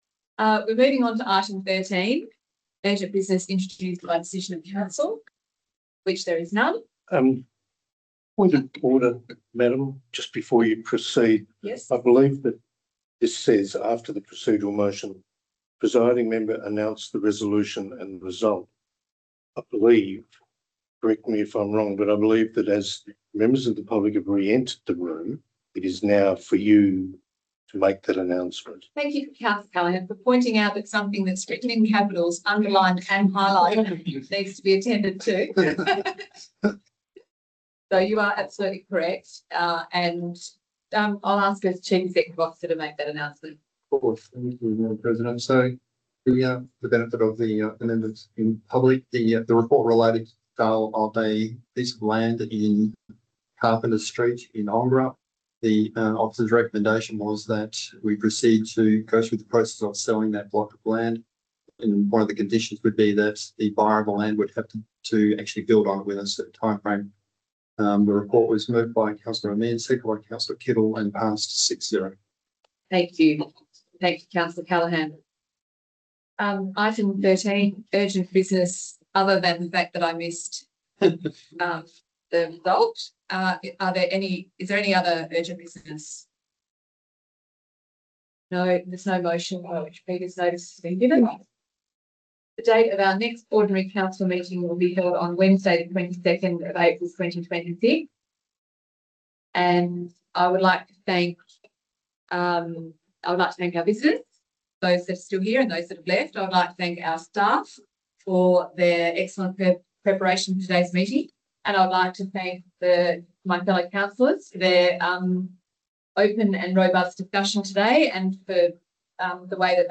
(Audio) Ordinary Council Meeting 25 March 2026 Recording PART 3 PUBLIC (2.97 MB)